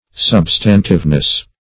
Meaning of substantiveness. substantiveness synonyms, pronunciation, spelling and more from Free Dictionary.
Search Result for " substantiveness" : The Collaborative International Dictionary of English v.0.48: Substantiveness \Sub"stan*tive*ness\, n. The quality or state of being substantive.